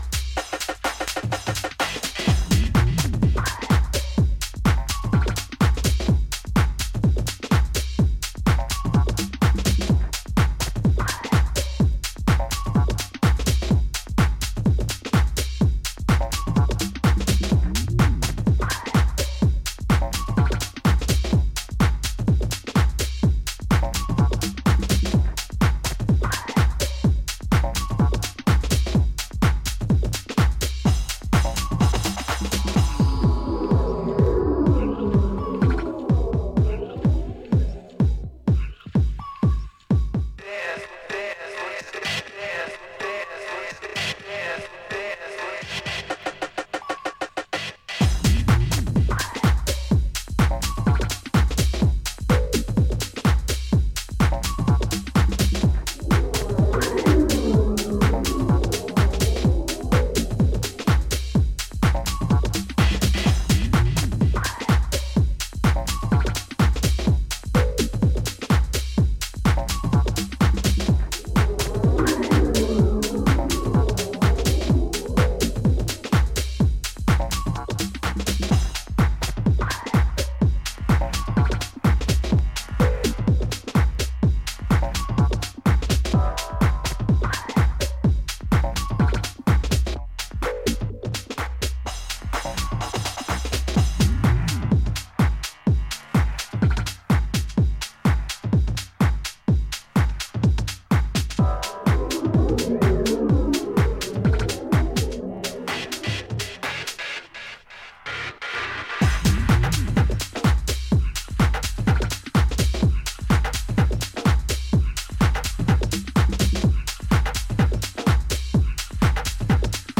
いずれの楽曲も当レーベルらしいカラーの、ソリッドかつ極めて圧の強いミニマル・ハウス！